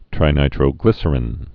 (trī-nītrō-glĭsər-ĭn)